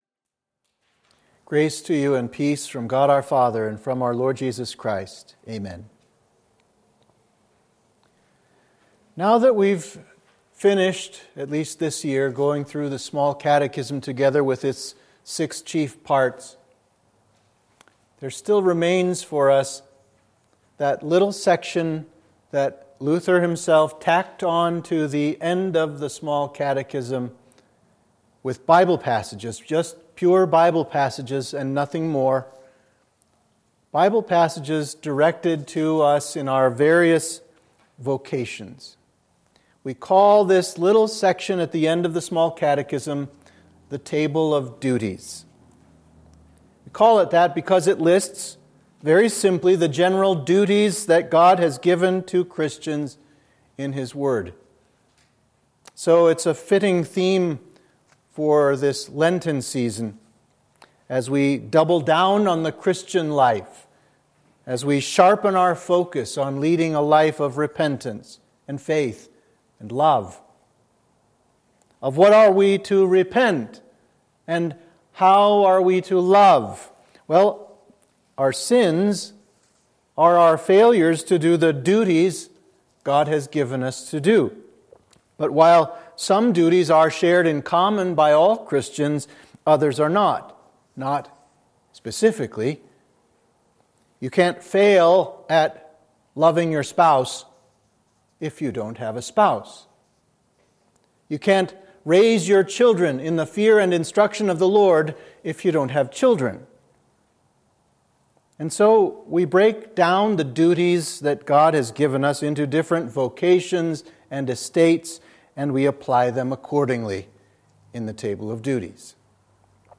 Sermon for Midweek of Invocavit – Lent 1